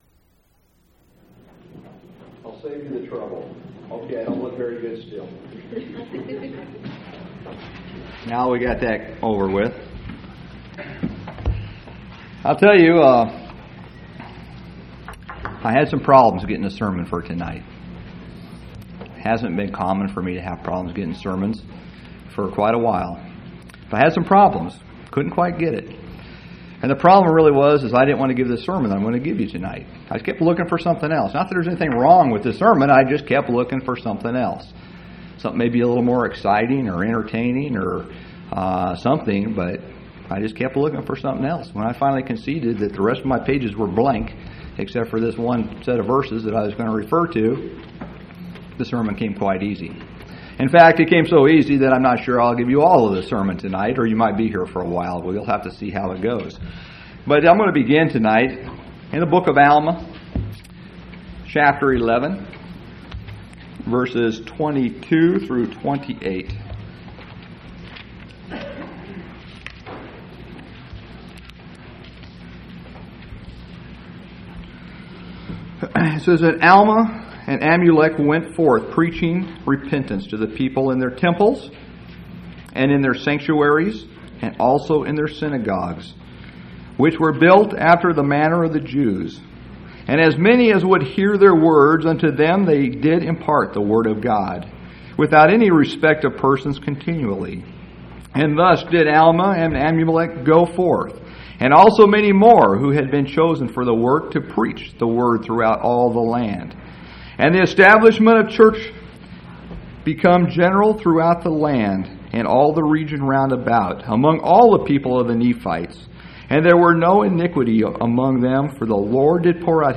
10/11/1998 Location: Phoenix Local Event
audio-sermons